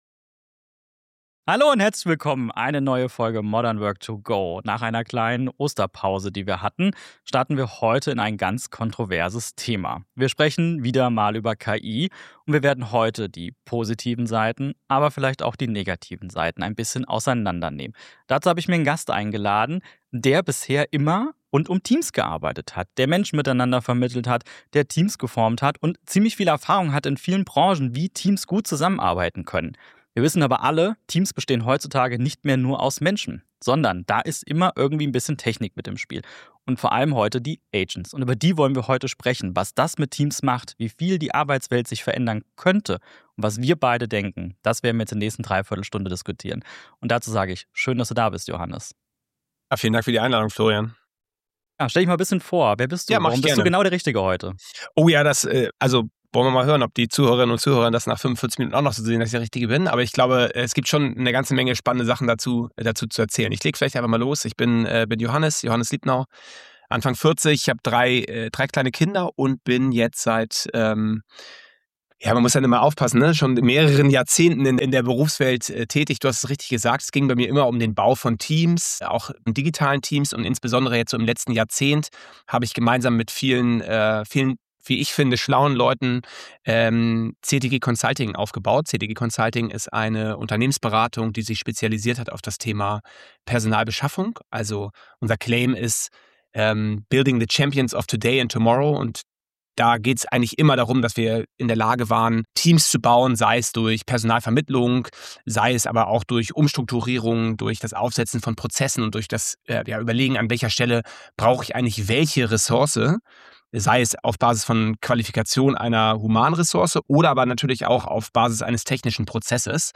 #103 Wie Agents wirklich zum Teammitglied werden - Interview